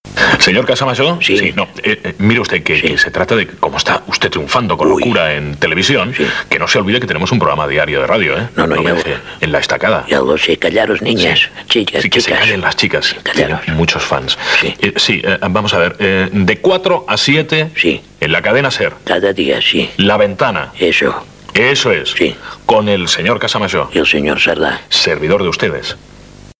Promoció